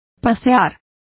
Complete with pronunciation of the translation of saunter.